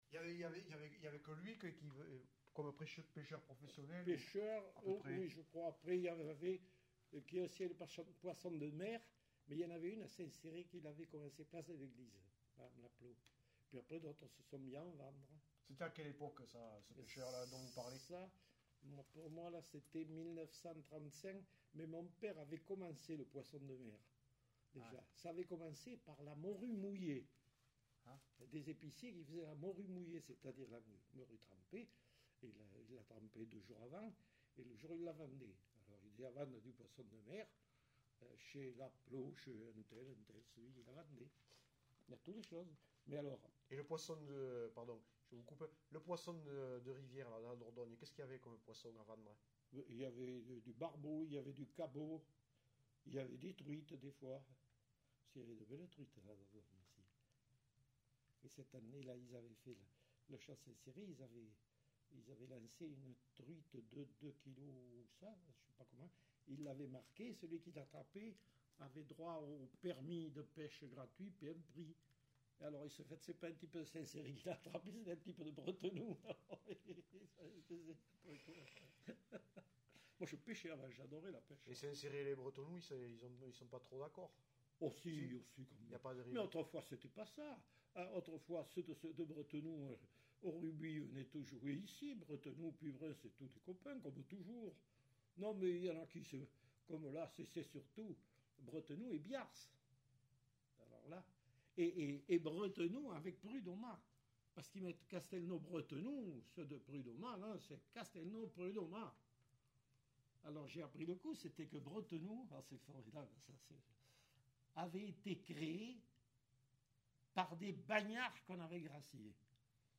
Lieu : Saint-Céré
Genre : témoignage thématique